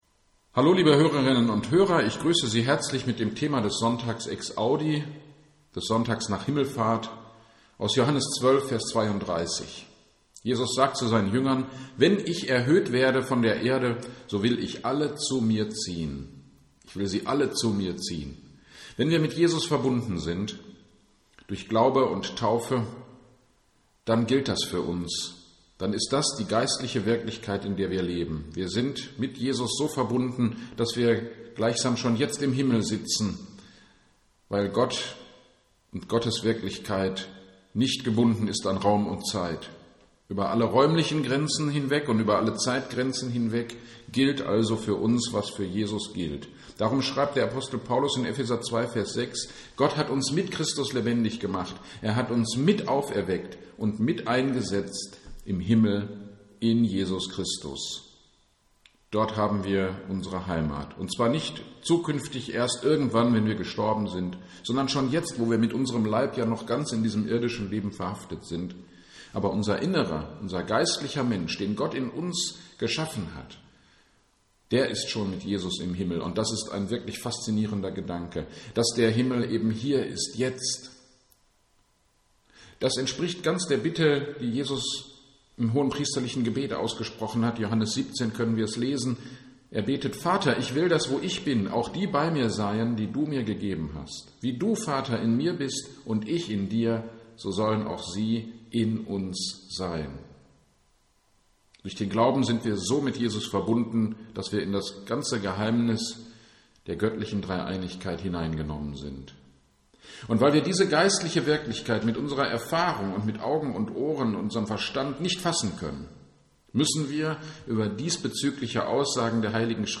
Gottesdienst am 16.05.2021 (Exaudi): "Neue Heimat"- Predigt zu Hebräer 11,8-16 - Kirchgemeinde Pölzig